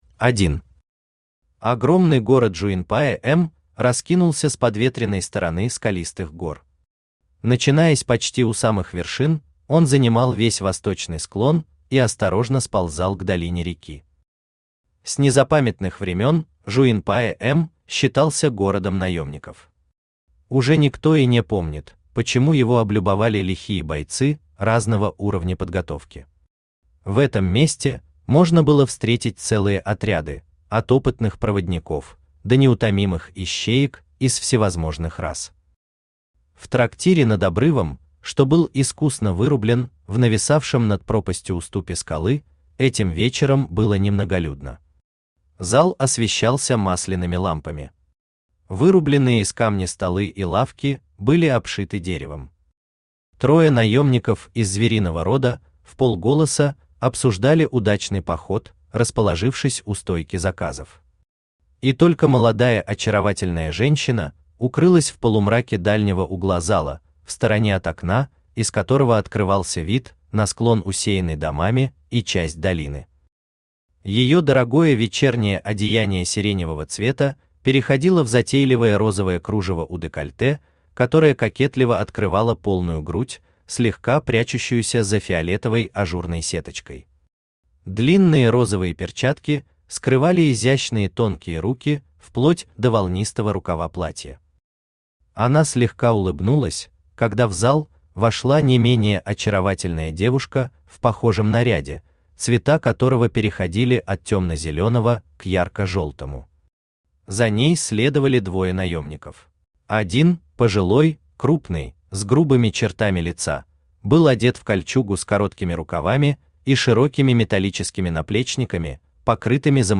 Aудиокнига Изгнанник Автор Сергей Витальевич Шакурин Читает аудиокнигу Авточтец ЛитРес.